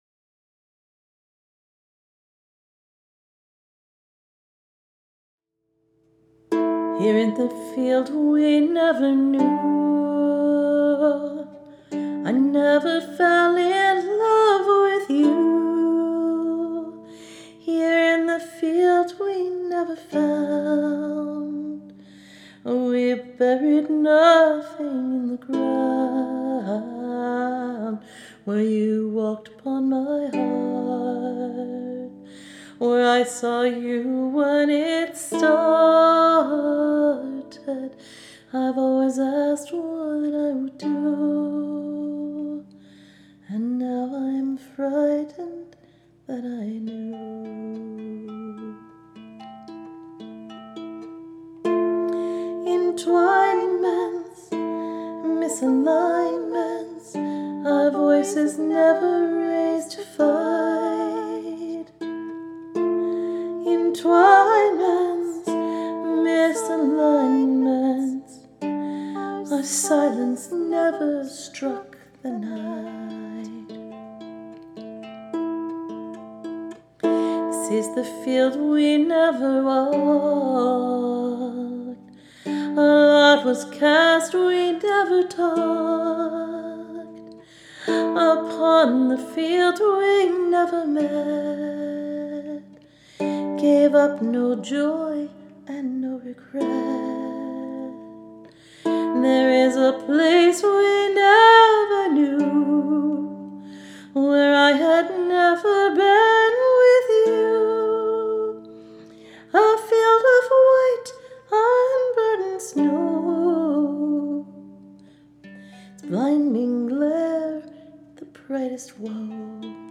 The simple harp accompaniment is played by me. This is a two-track piece – voice/harp and harmony.
It’s kind of a sad song for something that wasn’t even a thing.